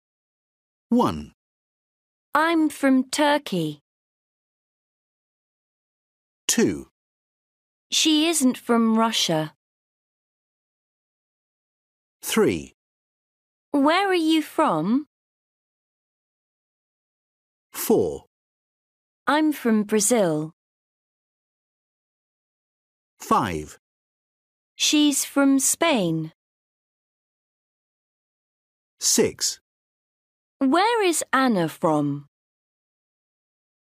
Listen and write the sentences. Then tick (✓) the correct pronunciation of from in each sentence.